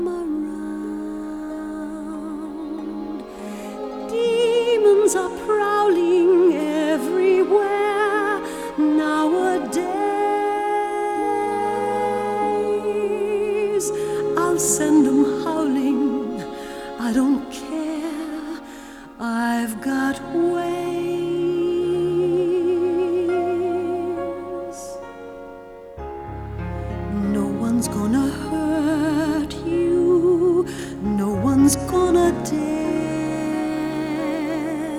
Жанр: Поп музыка / Рок / Соундтрэки